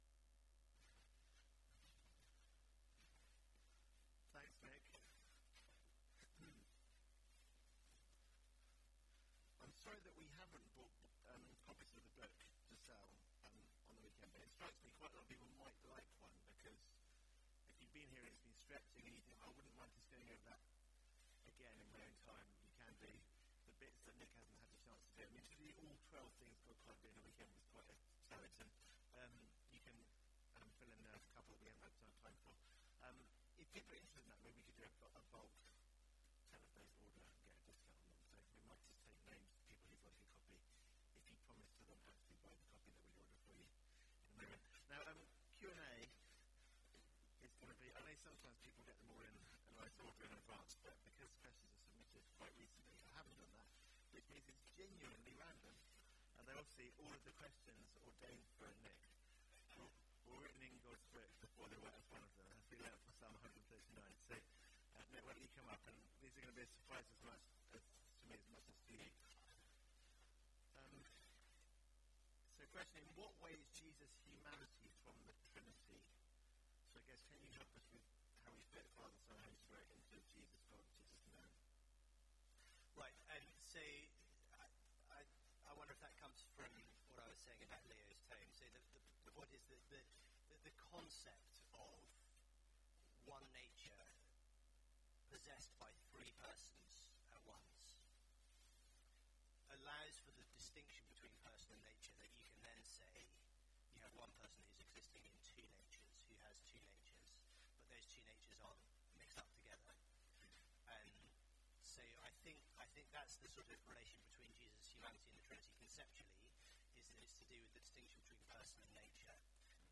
Q&A – Grace Church Greenwich